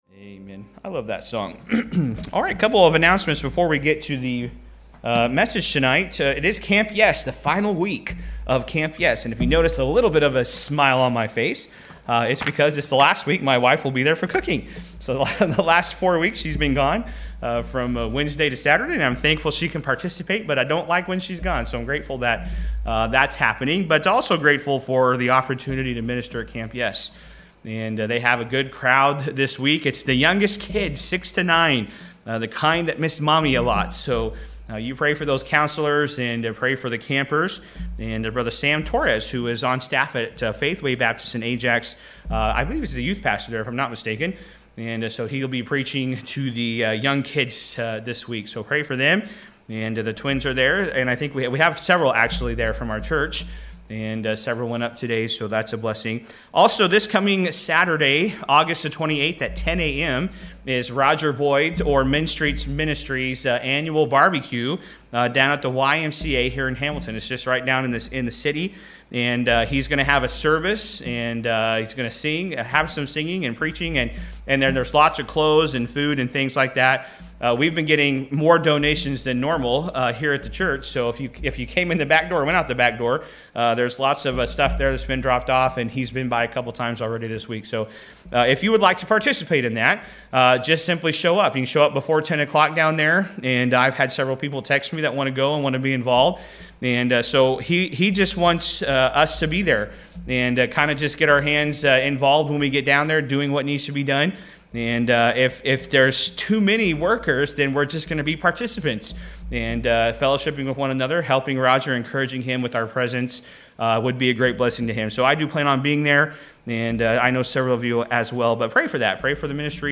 Sermons | New Testament Baptist Church